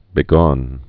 (bĭ-gôn, -gŏn)